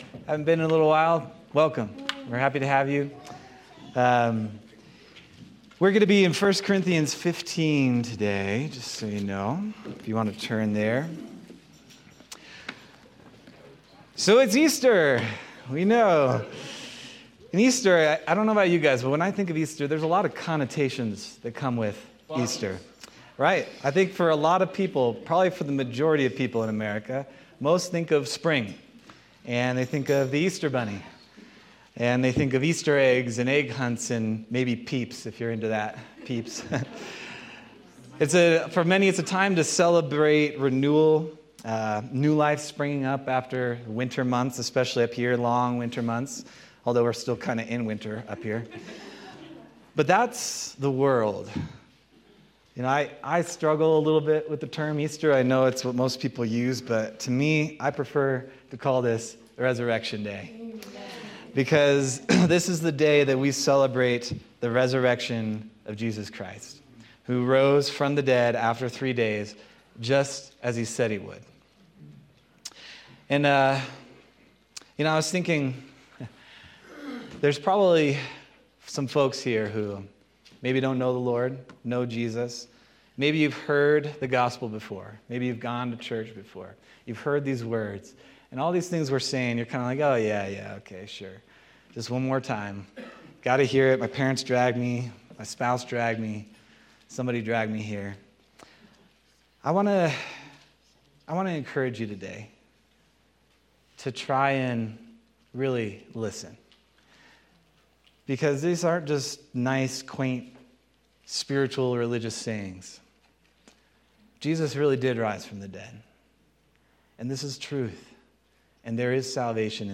Resurrection Day 2025 Sermon